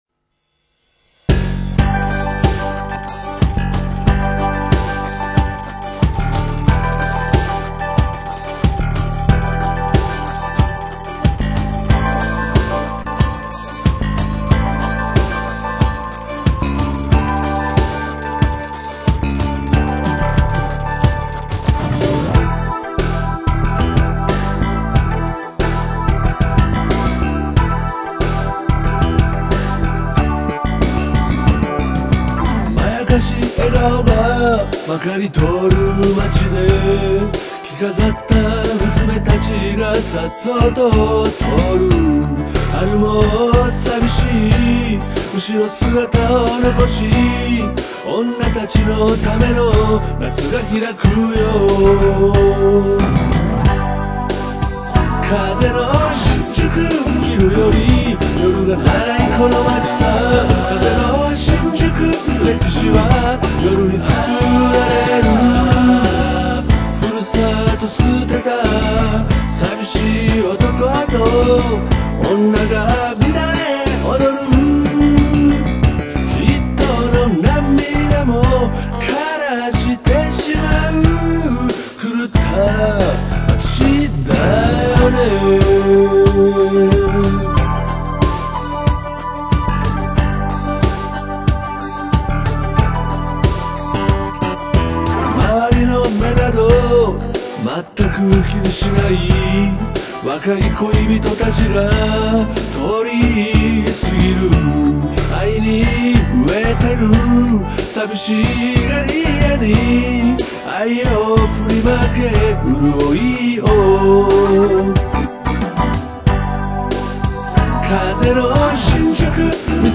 大学時代のバンドで演奏した代表曲のリメイクバージョン、当事のアレンジを踏襲した16ビート